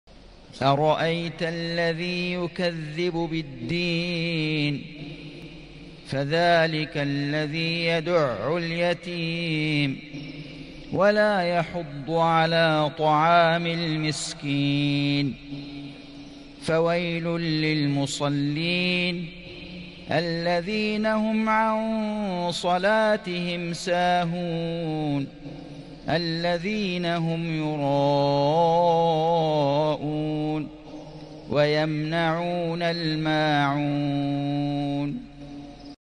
سورة الماعون > السور المكتملة للشيخ فيصل غزاوي من الحرم المكي 🕋 > السور المكتملة 🕋 > المزيد - تلاوات الحرمين